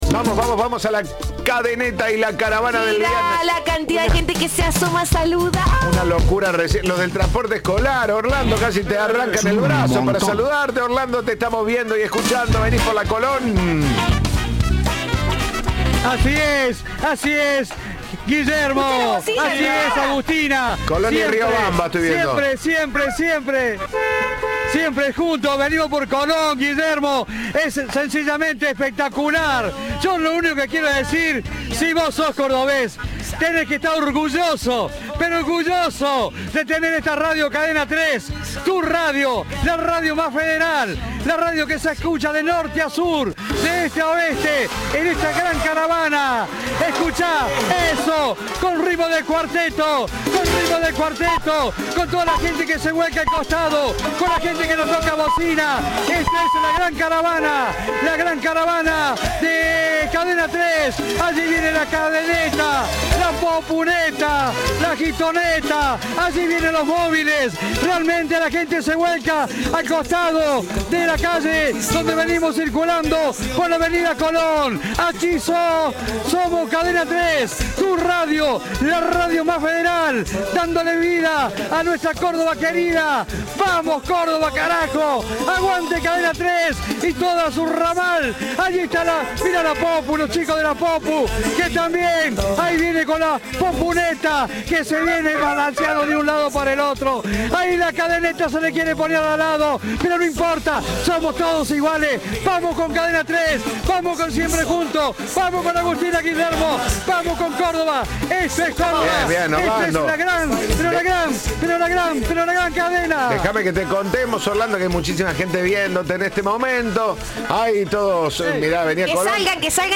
La caravana de la Gran Cadena Federal ya se siente en las calles de Córdoba